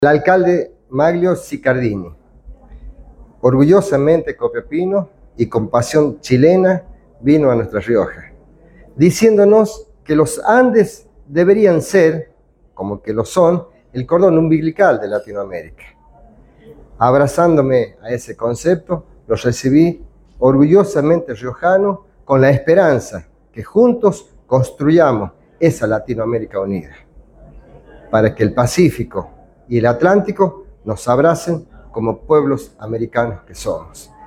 Armando-Molina-Intendente-de-la-rioja.mp3